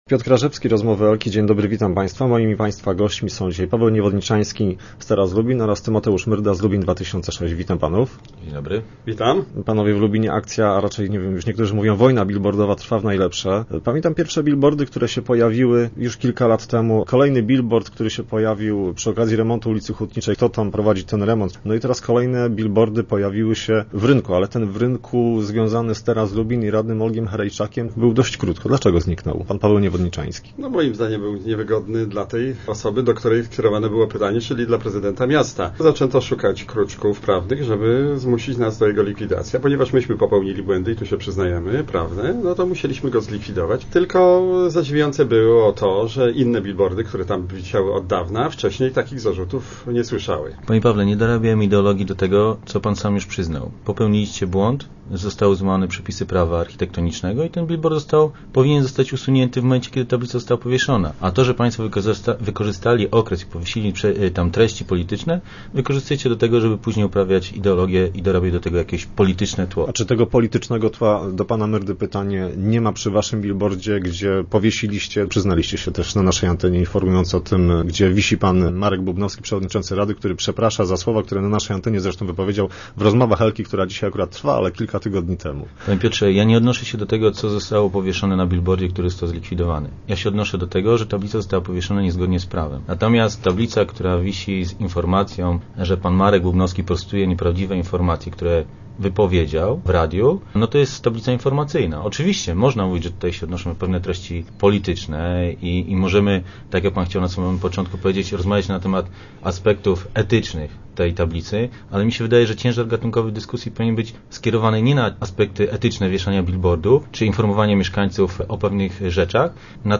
Obaj goście Rozmów Elki przekonywali, że wbrew obiegowej opinii, pojawiające się w mieście bilbordy, nie wynikają bezpośrednio ze zbliżającego się referendum.